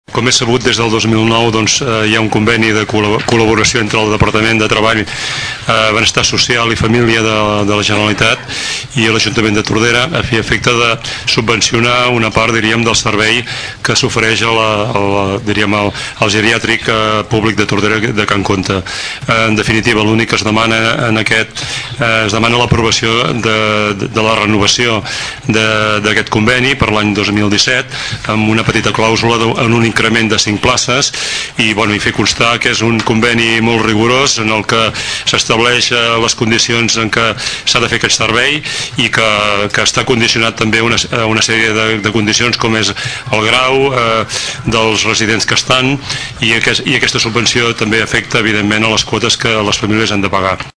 Escoltem el regidor d’Atenció Social i Serveis a la Persona, Àngel Pous.